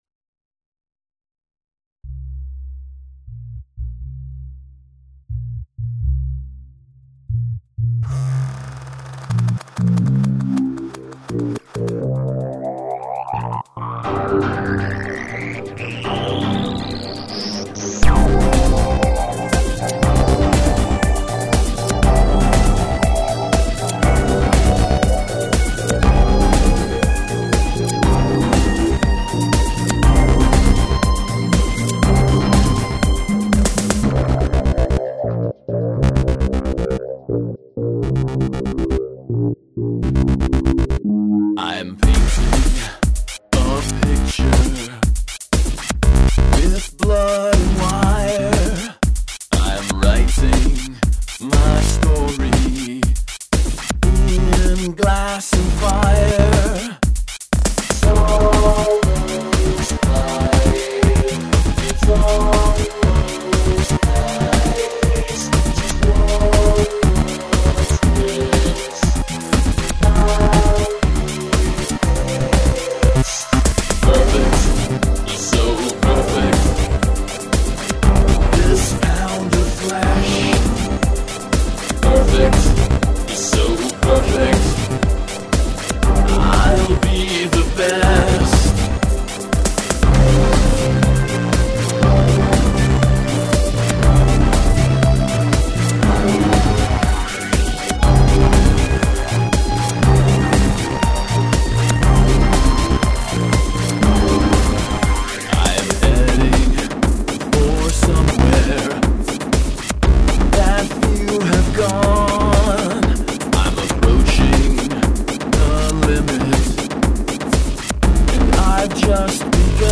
It's built around a whole-tone scale (made obvious by running up the scale early in the song).
It really sounds weird.